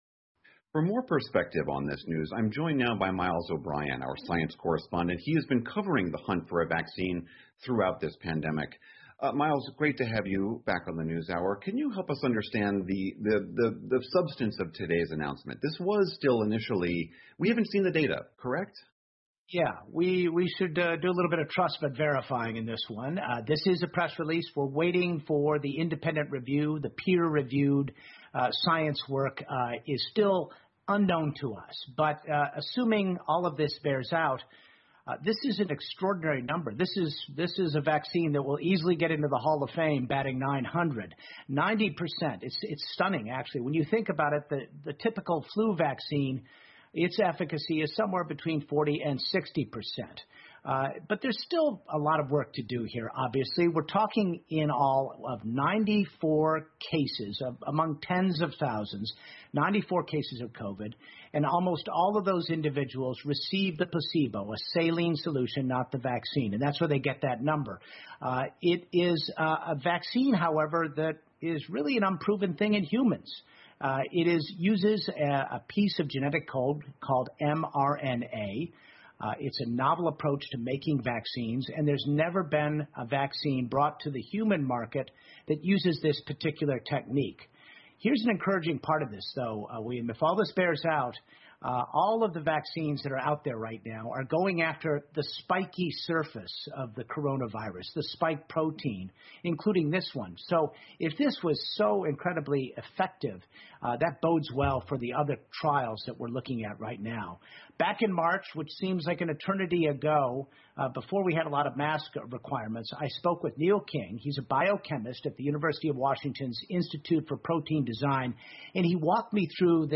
PBS高端访谈:新冠疫苗要在零下90度配送 听力文件下载—在线英语听力室